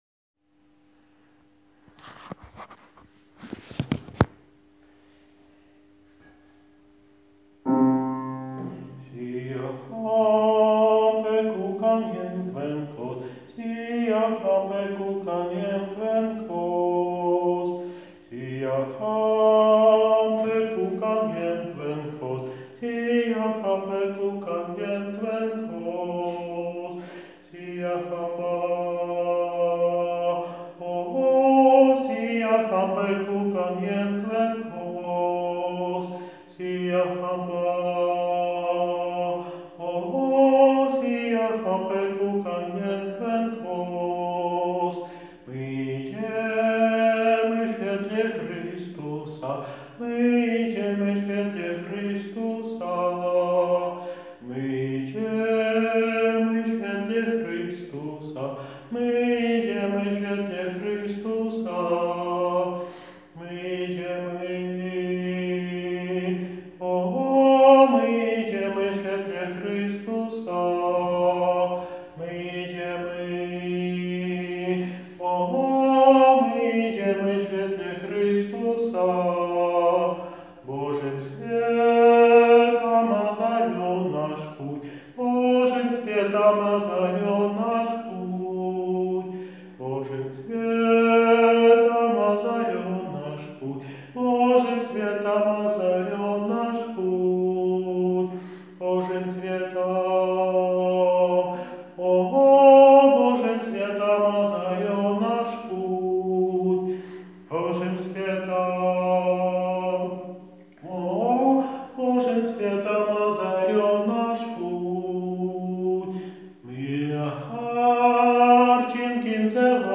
Siyahamba Sopran 1 - nagranie utworu z głosem nauczyciela ze słowami a capella (bez pomocy instrumentu)